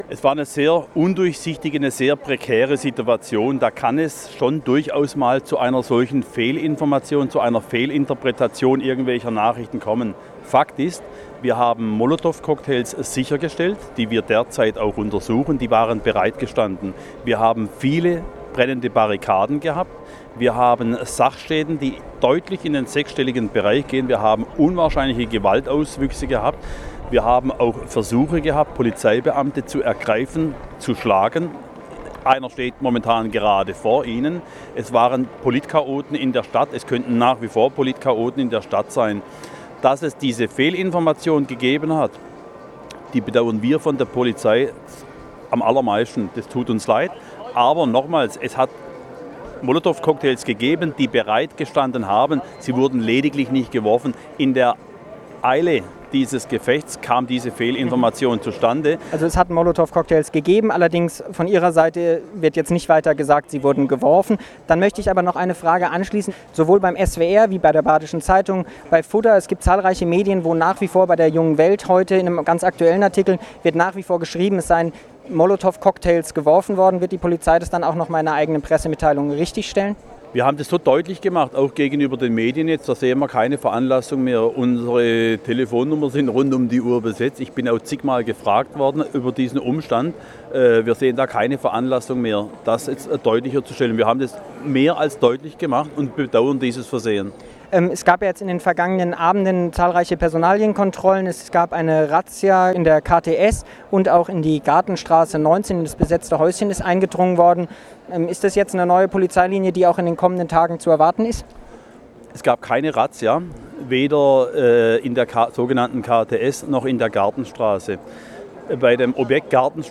Interview auf Radio Dreyeckland